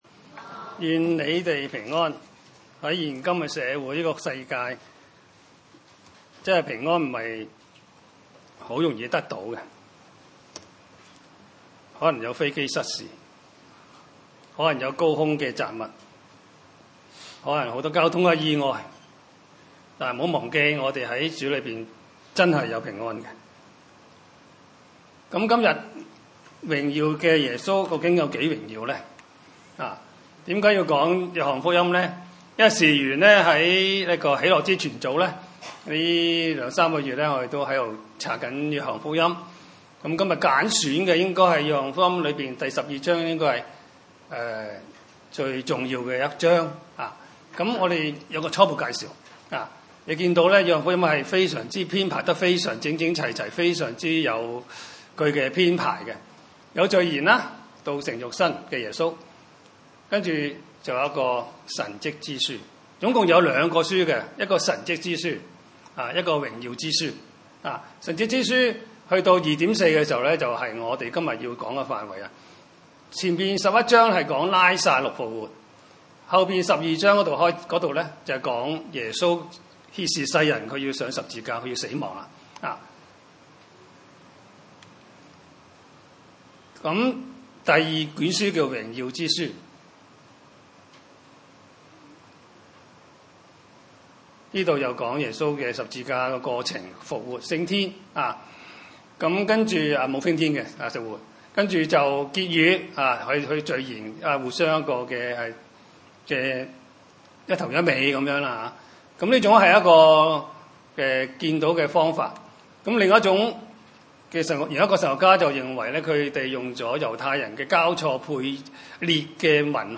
經文: 約翰福音 12:27-43 崇拜類別: 主日午堂崇拜 23 耶 穌 說 ： 人 子 得 榮 耀 的 時 候 到 了 。